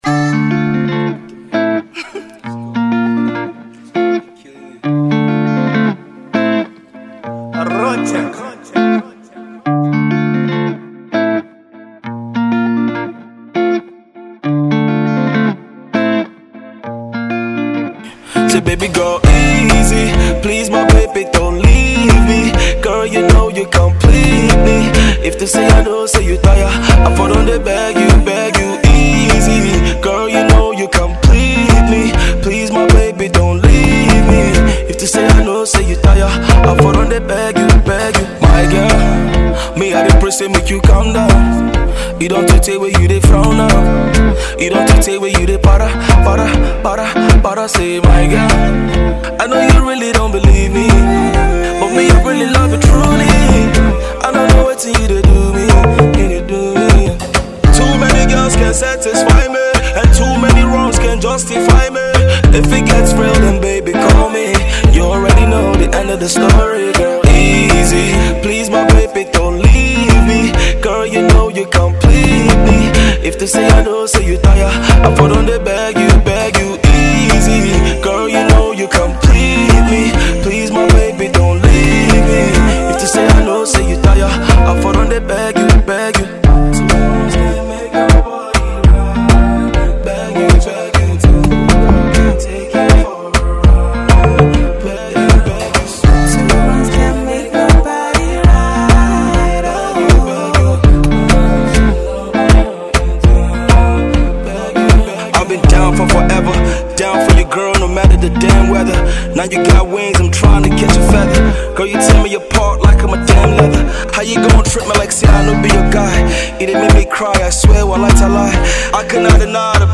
Nigerian talented rapper and songwriter